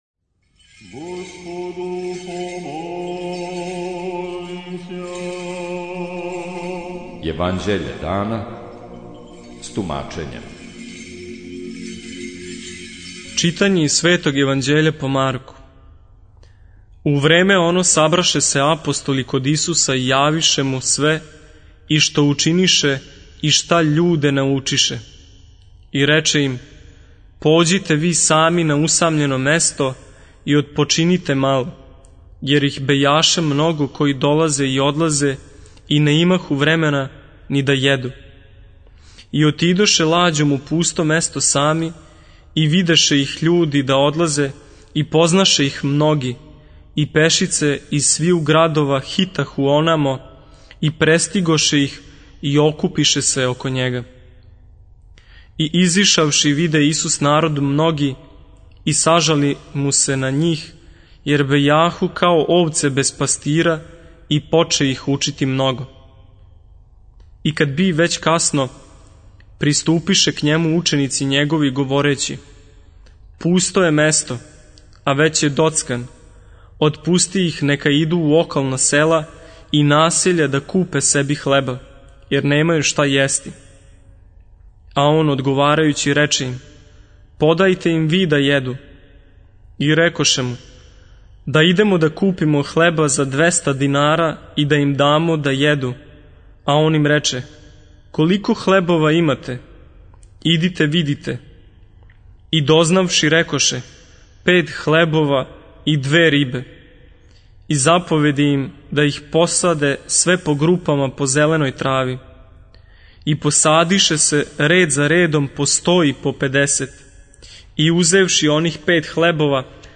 Читање Светог Јеванђеља по Марку за дан 28.02.2024. Зачало 61.